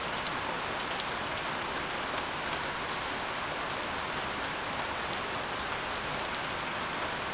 rain_medium_loop_02.wav